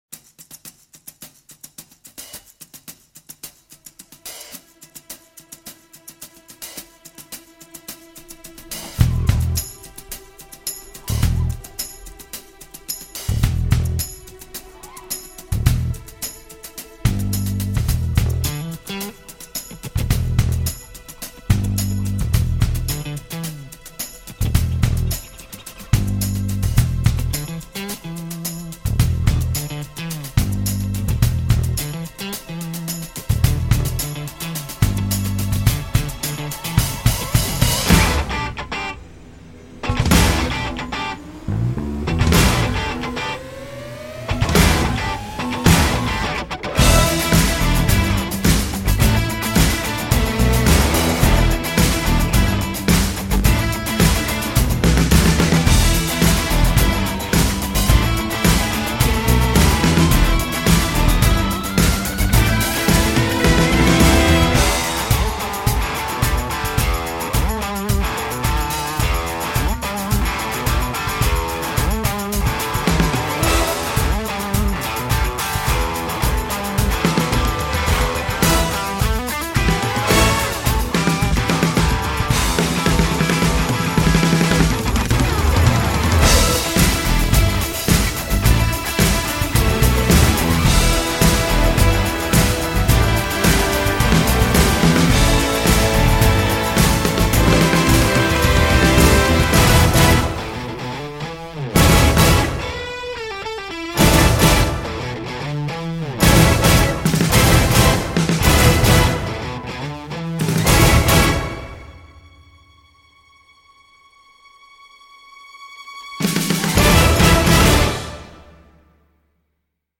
Mais là, c’est efficace, musclé et fun.